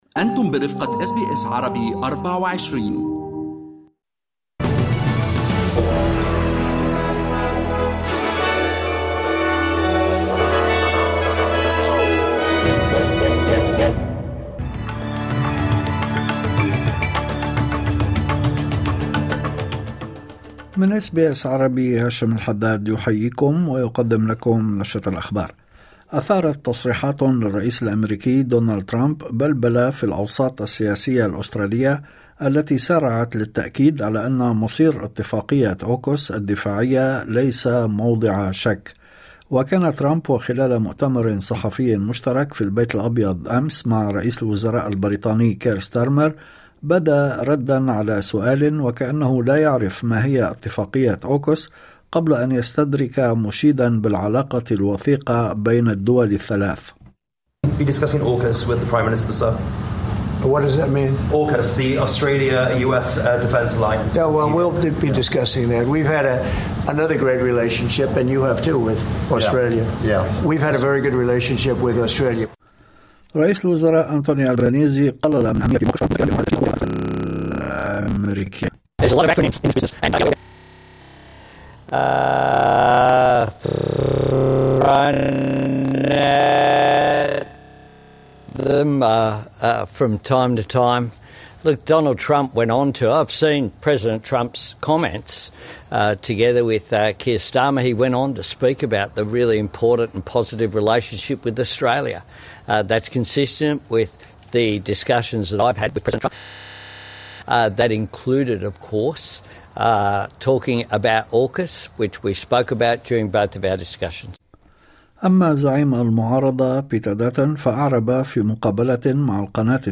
نشرة الأخبار